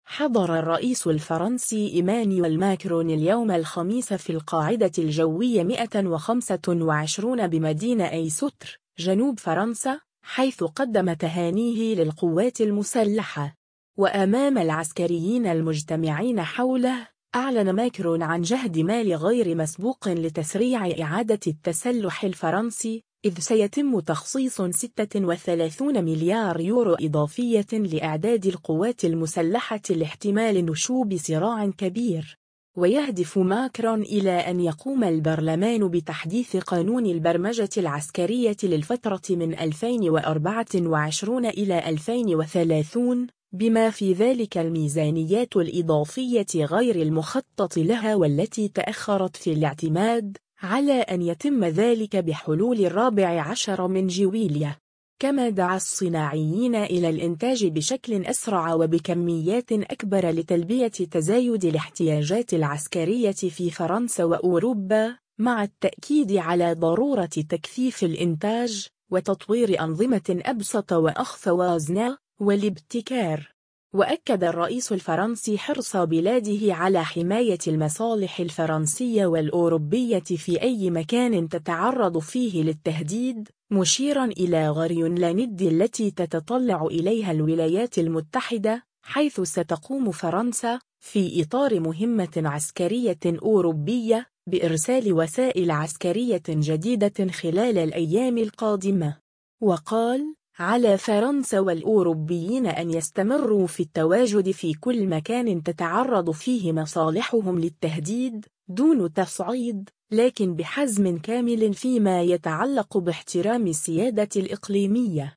حضر الرئيس الفرنسي إيمانويل ماكرون اليوم الخميس في القاعدة الجوية 125 بمدينة إيستر، جنوب فرنسا، حيث قدّم تهانيه للقوات المسلحة.
وأمام العسكريين المجتمعين حوله، أعلن ماكرون عن جهد مالي غير مسبوق لتسريع إعادة التسلح الفرنسي، إذ سيتم تخصيص 36 مليار يورو إضافية لإعداد القوات المسلحة لاحتمال نشوب صراع كبير.